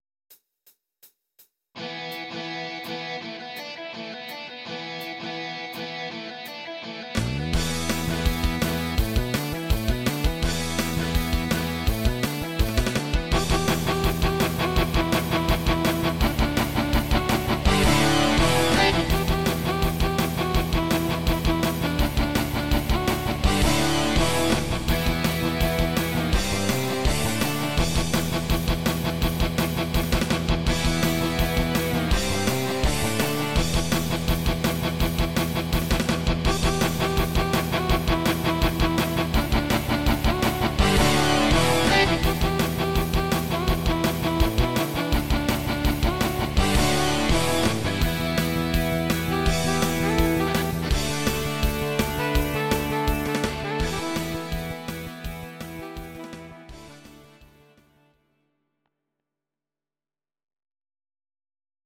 Audio Recordings based on Midi-files
Rock, 1970s